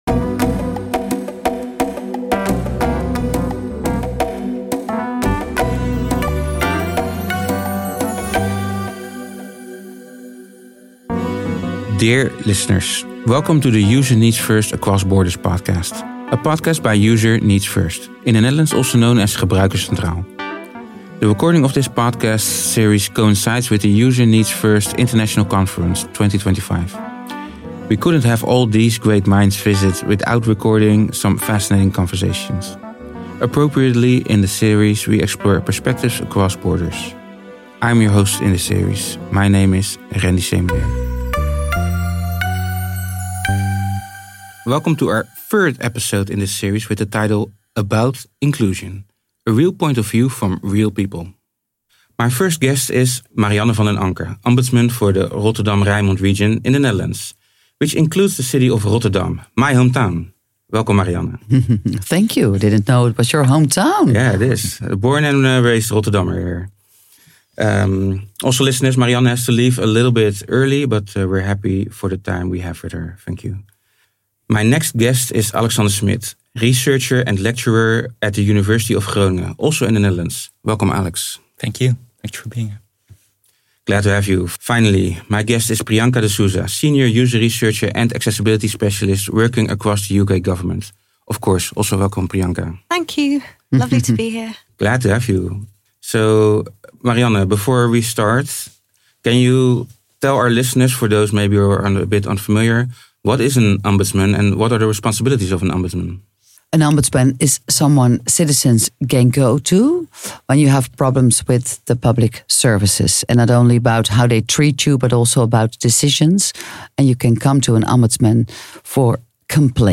In this podcast series ‘User Needs First Across Borders’ we talk to international speakers who were guests at the User Centered conference in April 2025.